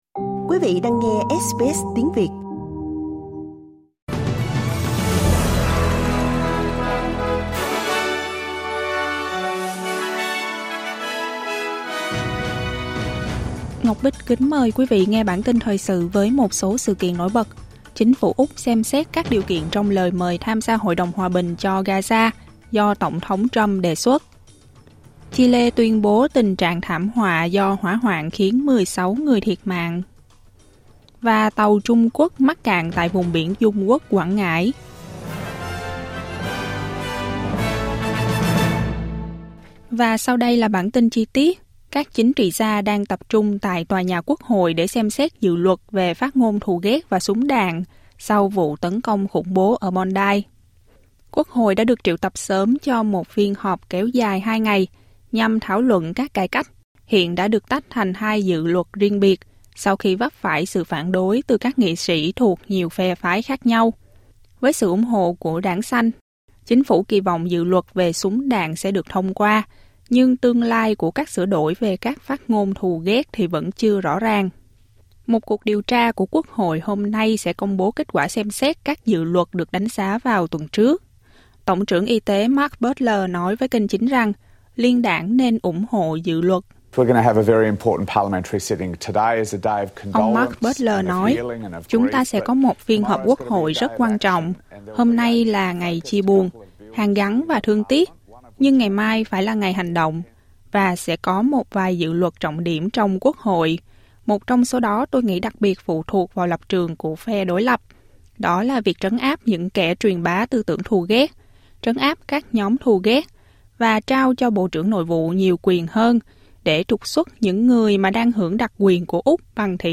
Một số tin tức trong bản tin thời sự của SBS Tiếng Việt.